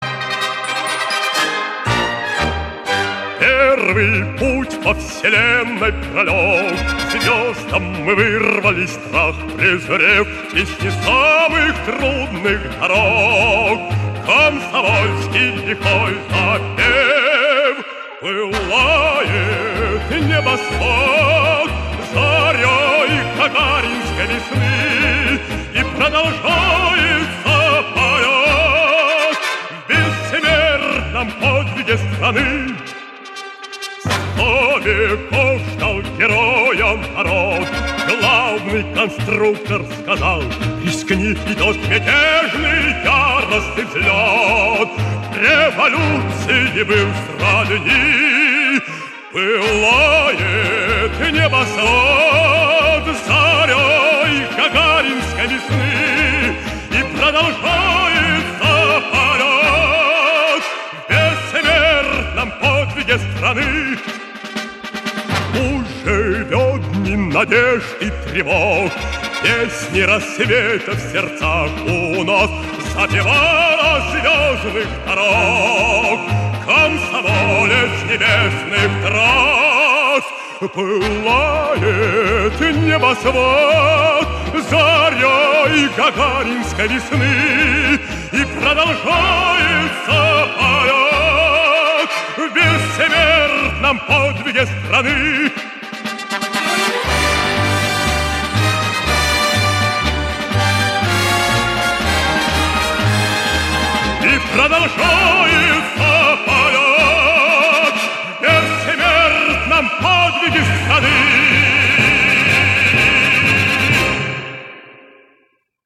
Жанр: Рор
Формат: Vinil, EP, Mono, 33 ⅓, Album
Стиль: Vocal